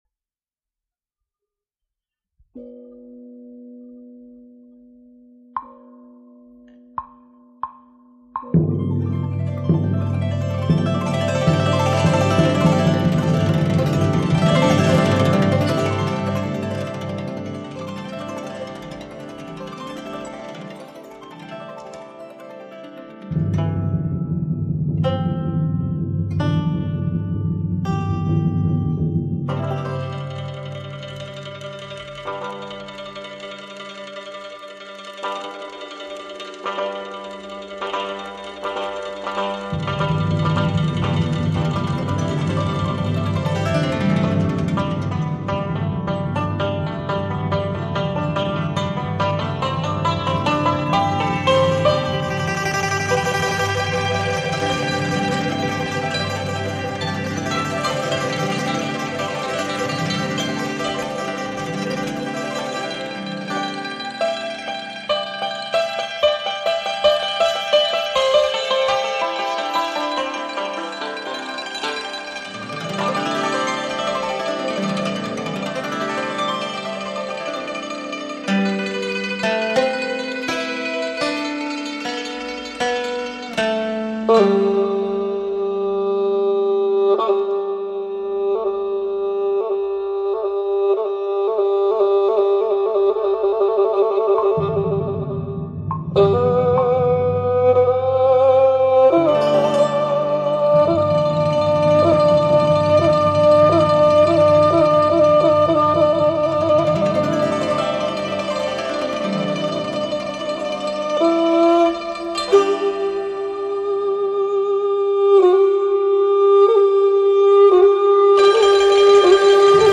中胡与弹拨